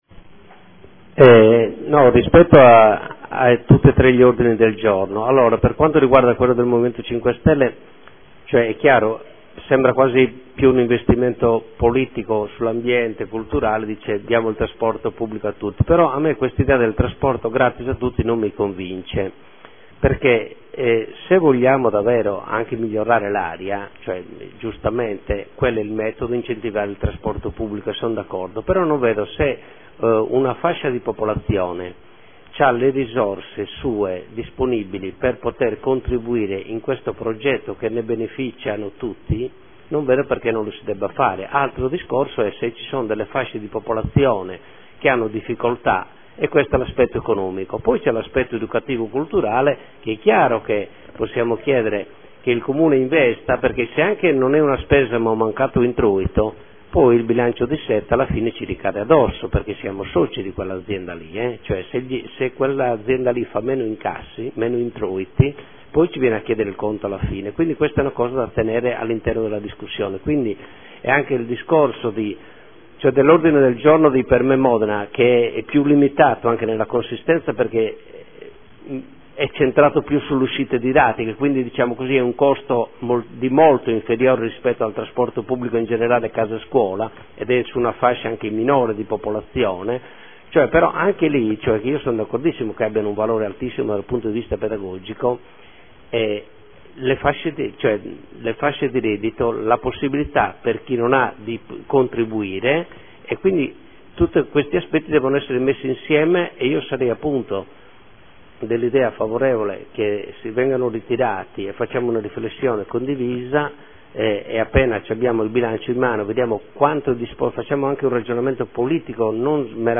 Seduta del 13/11/2014 Dibattito. Ordini del giorno trasporto scolastico.